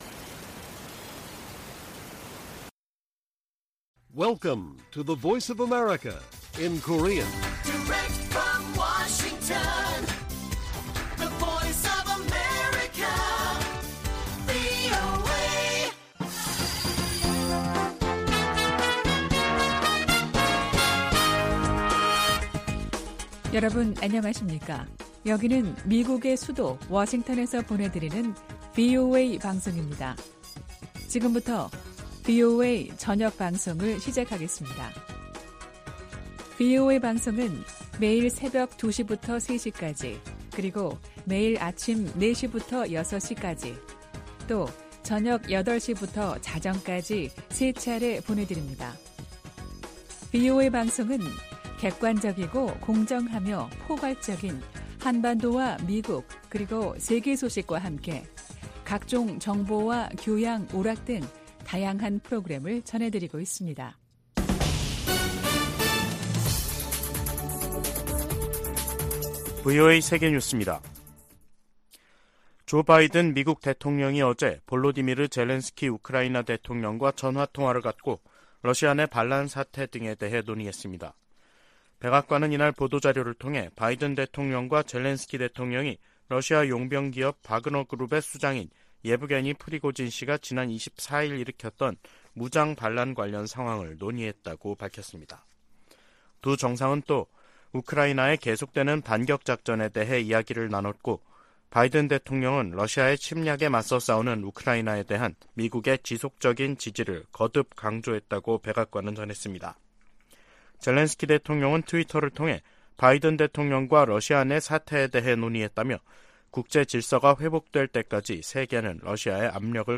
VOA 한국어 간판 뉴스 프로그램 '뉴스 투데이', 2023년 6월 26일 1부 방송입니다. 북한 동창리 서해위성발사장의 새 로켓 발사대 주변에서 새로운 움직임이 포착돼 새 발사와의 연관성이 주목됩니다. 미국은 중국에 대북 영향력을 행사할 것을 지속적으로 촉구하고 있다고 백악관이 밝혔습니다. 국무부는 북한 식량난이 제재 때문이라는 러시아 대사의 주장에 대해 북한 정권의 책임을 다른 곳으로 돌리려는 시도라고 비판했습니다.